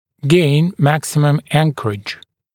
[geɪn ‘mæksɪməm ‘æŋkərɪʤ][гейн ‘мэксимэм ‘энкэридж]добиться максимальной опоры